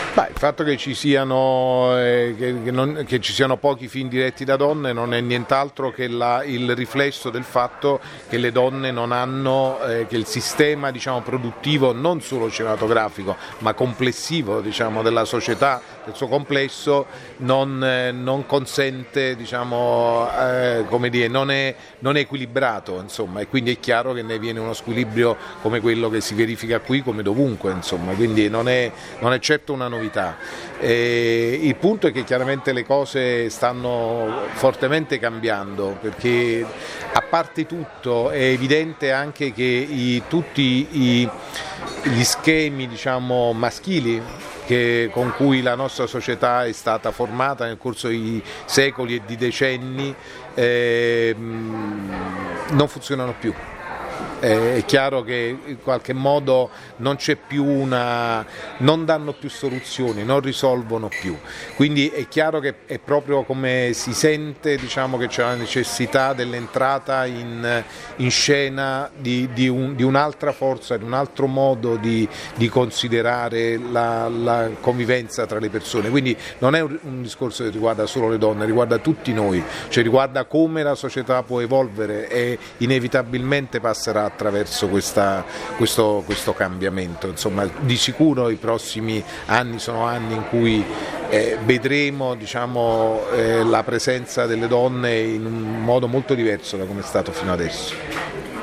capri-revolution-mario-martone-sullemancipazione-femminile.mp3